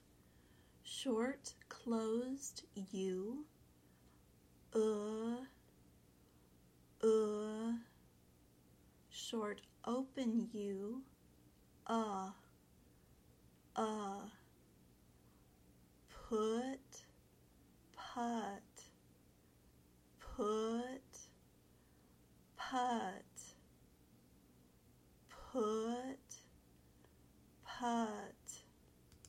The short u sound you hear in the word put is called the short closed u. It is also called the other u. It uses the IPA symbol /ʊ/.
Listen to this audio of the minimal pair put and putt. All the sounds are the same except for the short closed u of put and the short open u of putt.
put-putt.mp3